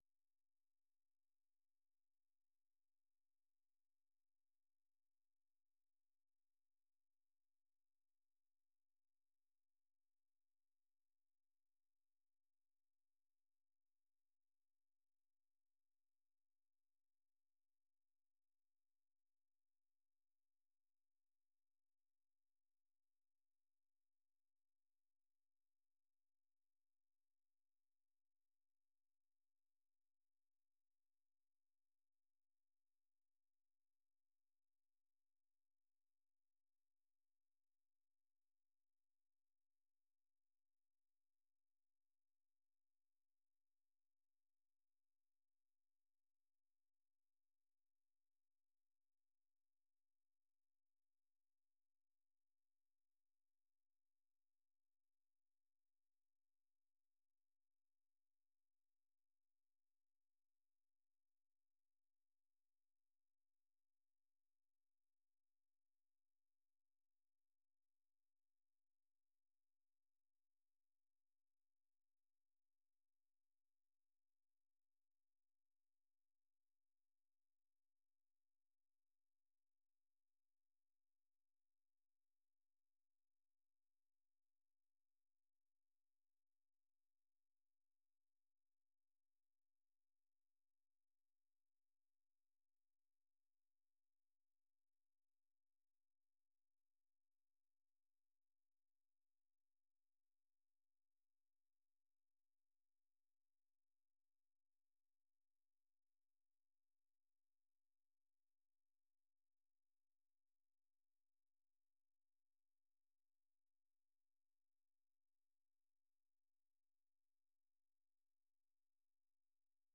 VOA 한국어 아침 뉴스 프로그램 '워싱턴 뉴스 광장'입니다.